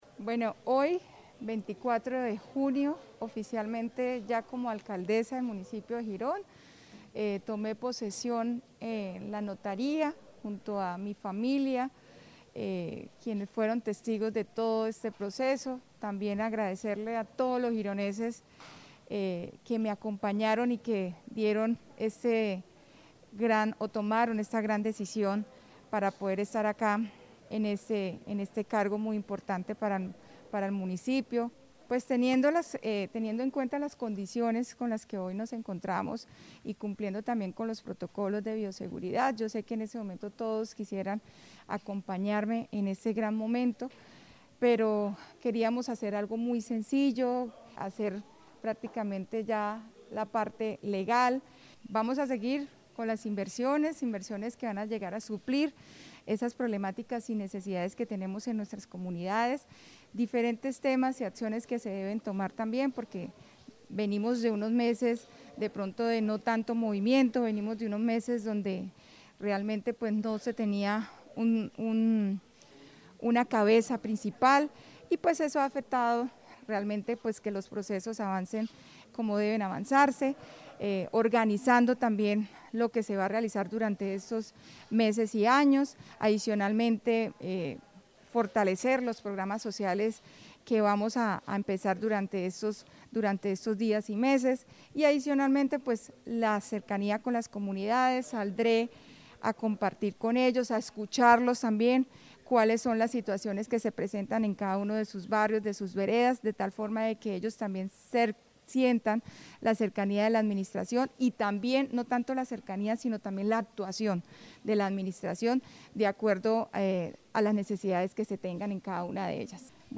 Yulía Rodríguez, Alcaldesa de Girón.mp3